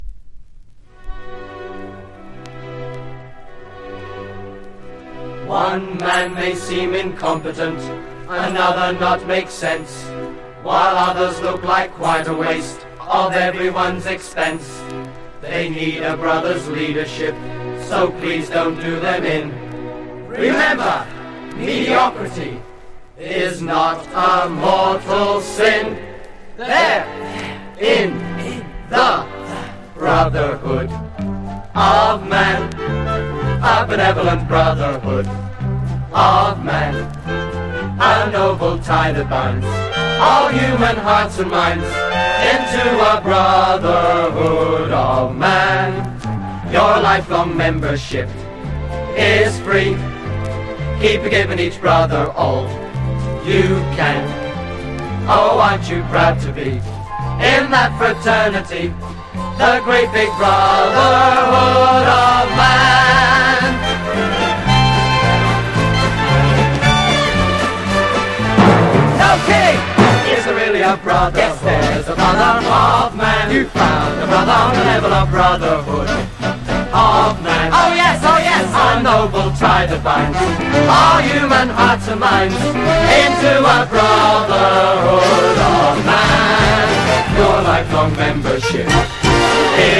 コーラスグループ